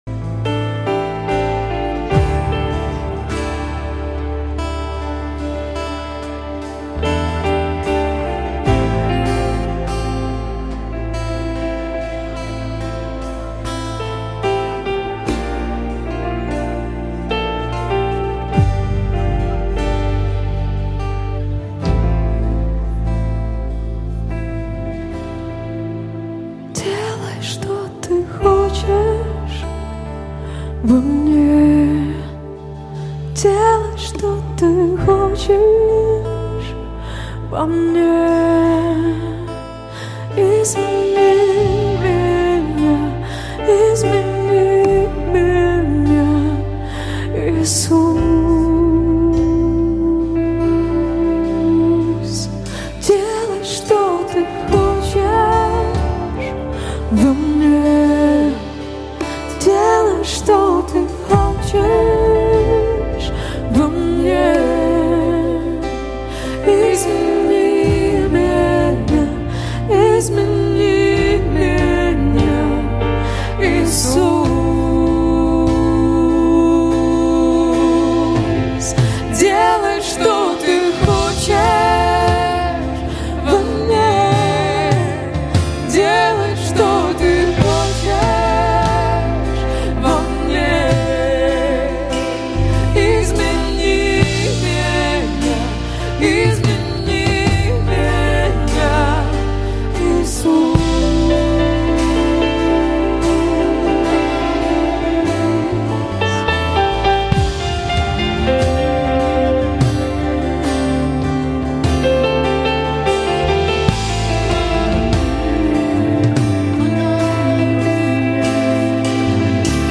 2302 просмотра 1139 прослушиваний 157 скачиваний BPM: 72